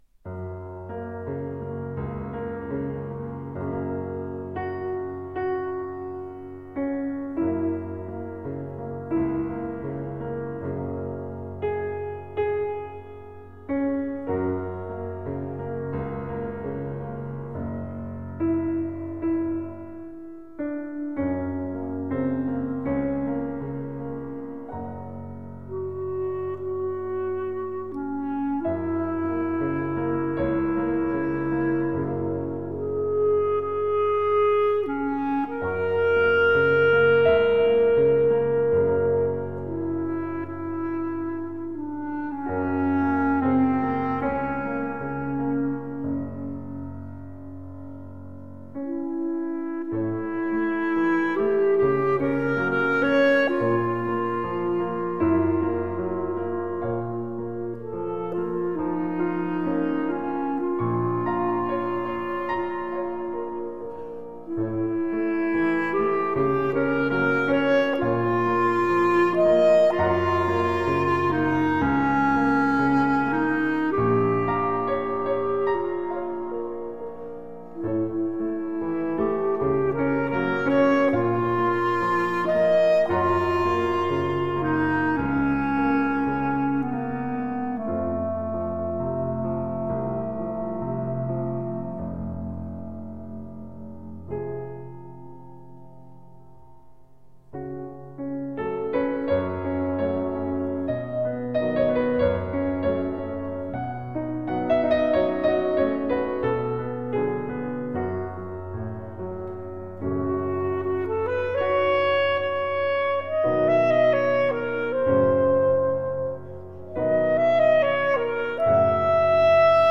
Exquisite original melodic pieces.
clarinet
piano